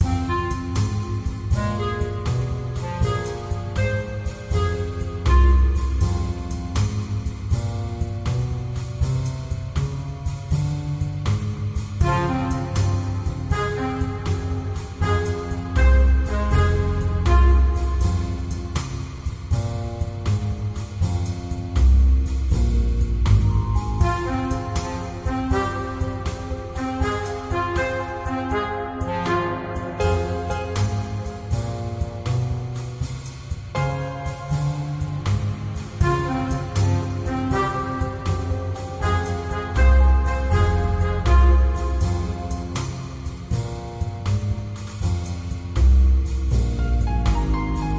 游戏音乐 爵士乐循环播放 " 爵士乐循环播放
描述：使用Kontakt默认库创建的80 BPM Jazz循环。
Tag: 音乐 爵士乐 游戏 循环